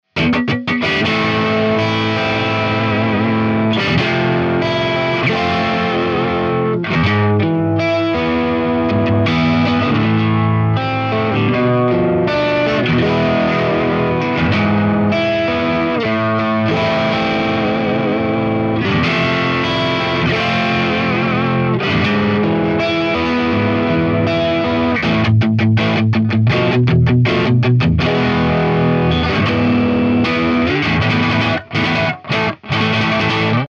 It's incredible sounding when pushed! Bright, edgy and alot of attack!
Chords
RAW AUDIO CLIPS ONLY, NO POST-PROCESSING EFFECTS